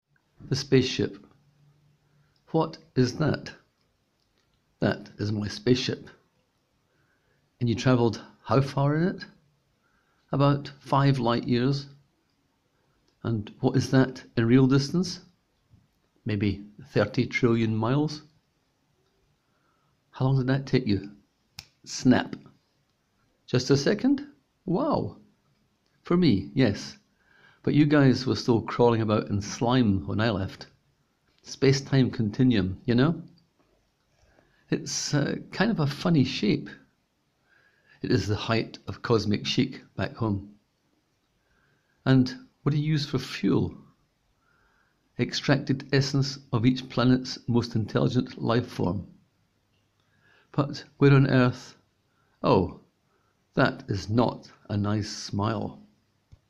Click here to hear the author read the tale: